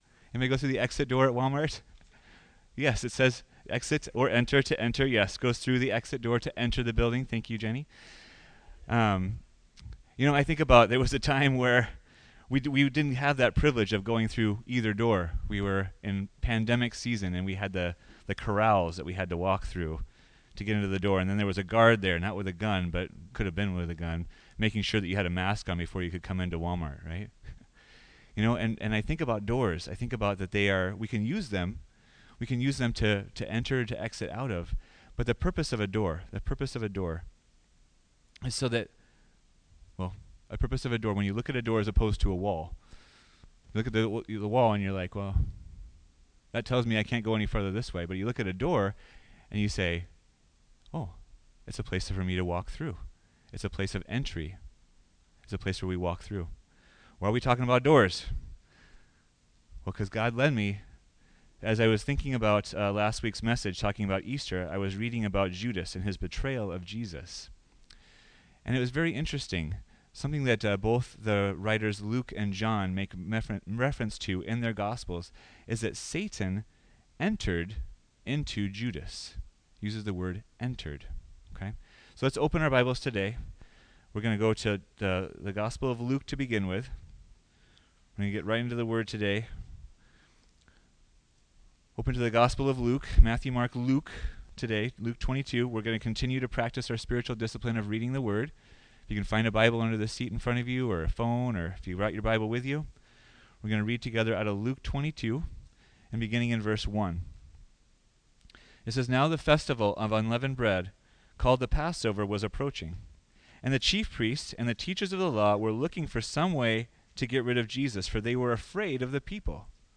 Footholds Part 1 – Don’t Give the Enemy a Chance – Friendship Church